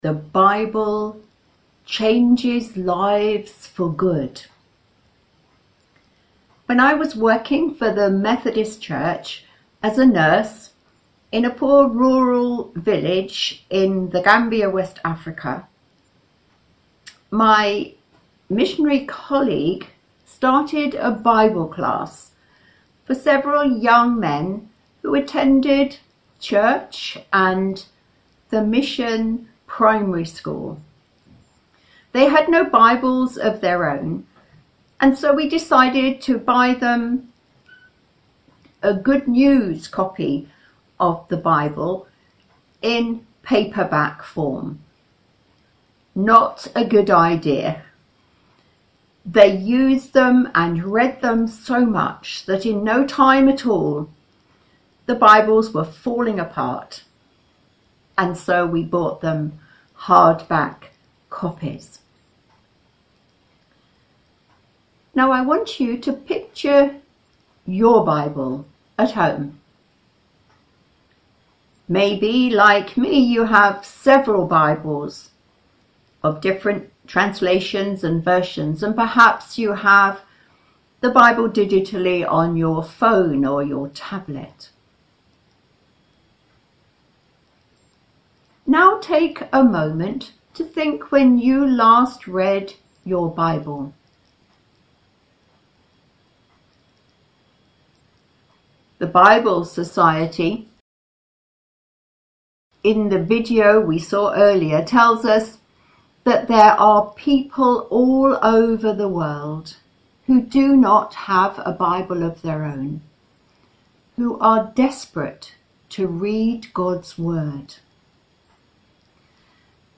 latestsermon.mp3